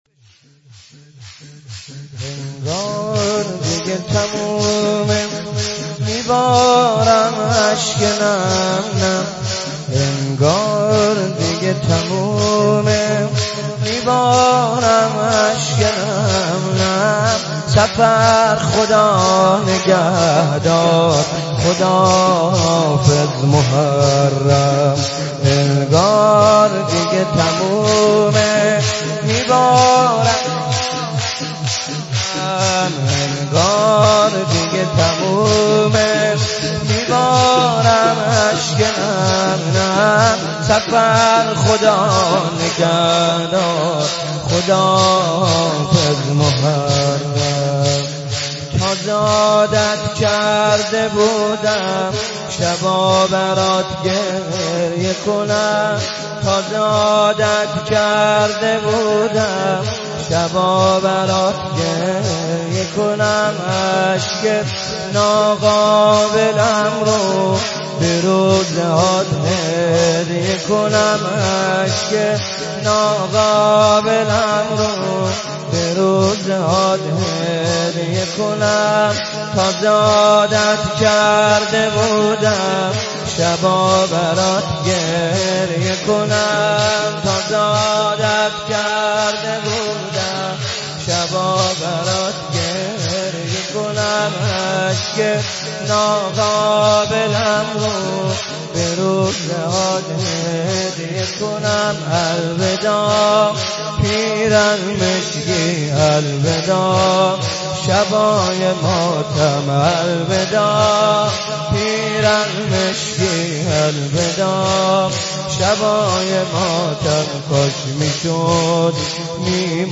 صفر خدانگهدار خداحافظ محرم دانلود مداحی خداحافظی با محرم و صفر